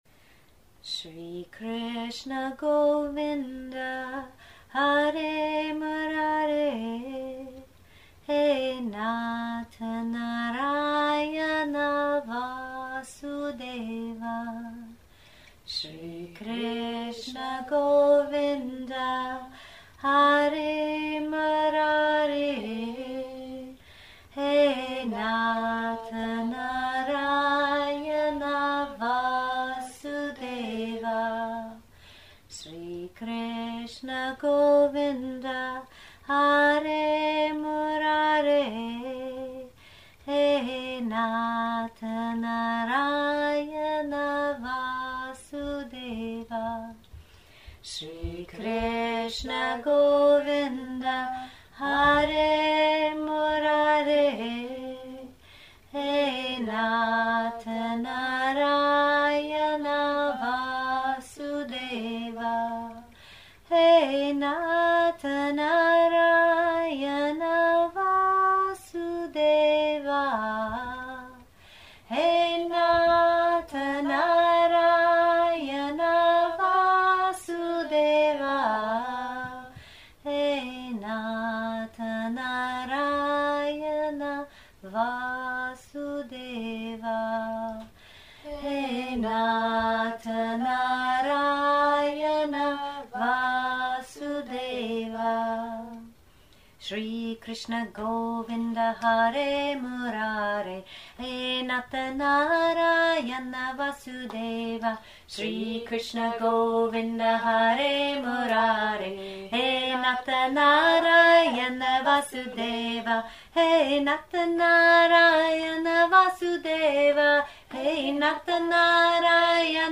Sanskrit Chants and Mantras